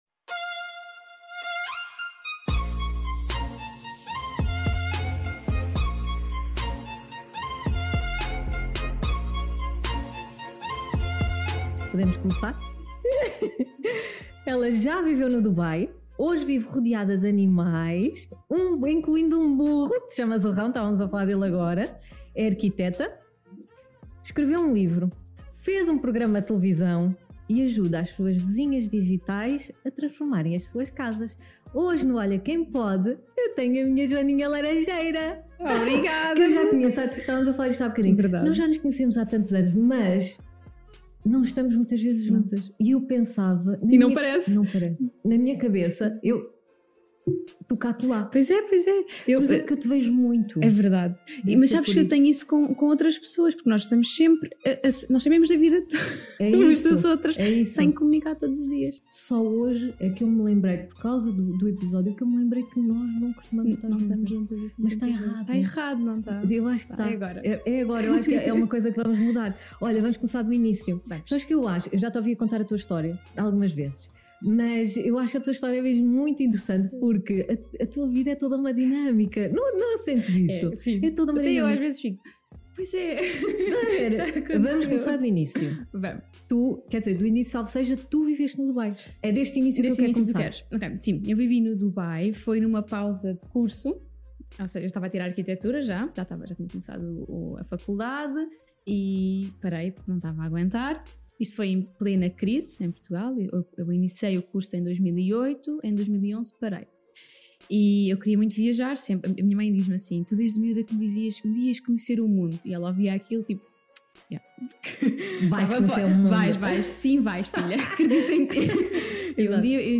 Falámos sobre a sua profissão, o ter-se despedido, trabalhar em casal, ter um programa de televisão, ter um livro, e muitas outras curiosidades. É uma conversa descontraída, inspiradora e leve, que a torna a companhia perfeita!